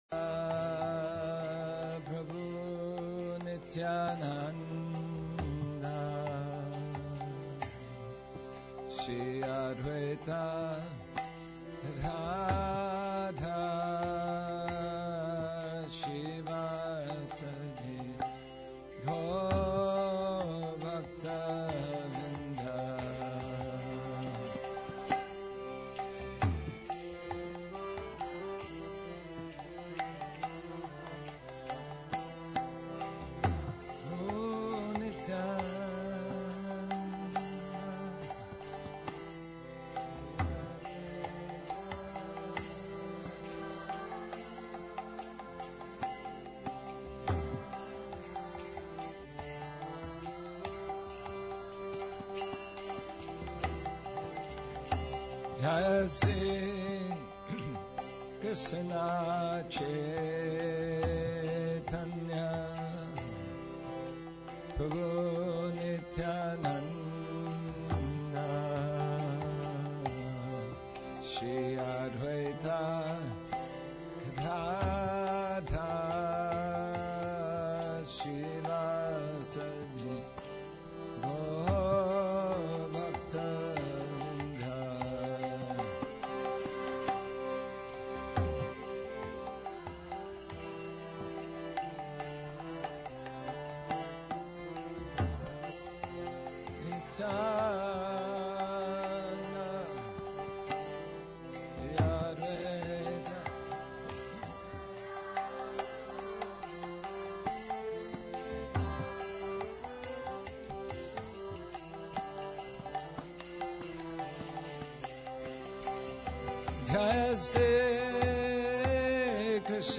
Kirtan
2015 Seattle Japa Retreat